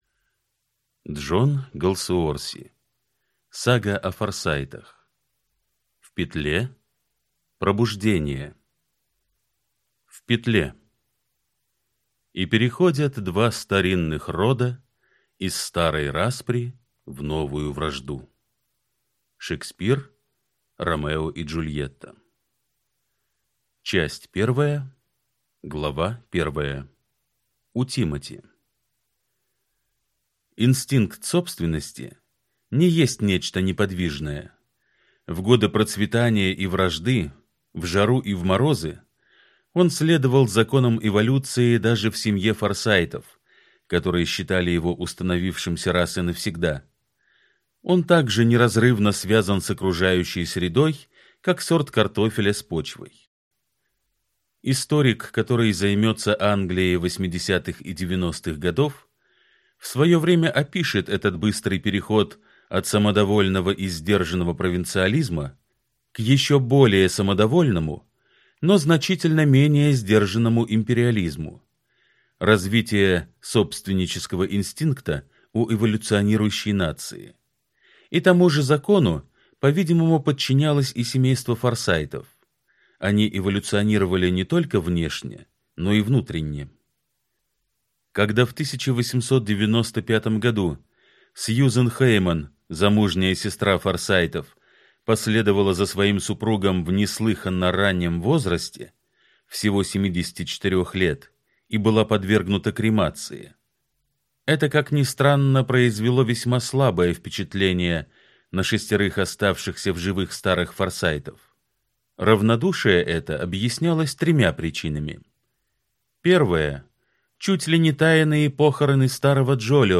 Аудиокнига Сага о Форсайтах. В петле. Пробуждение | Библиотека аудиокниг